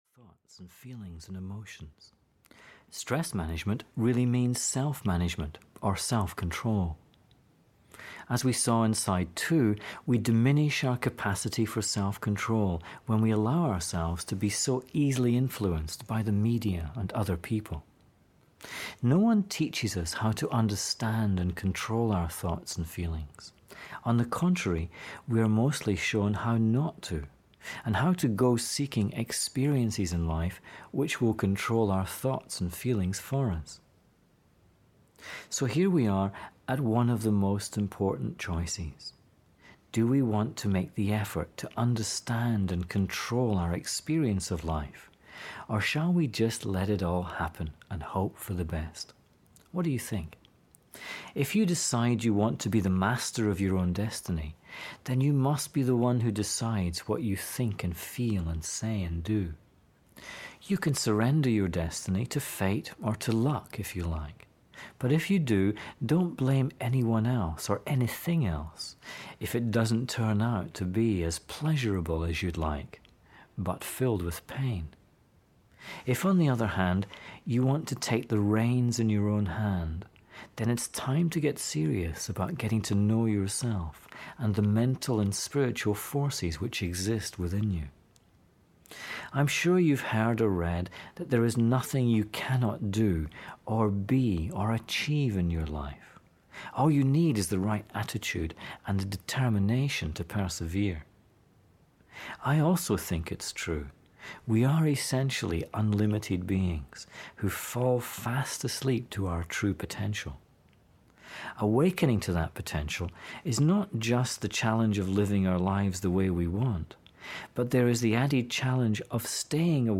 Audio knihaStress Free Living 2 (EN)
Ukázka z knihy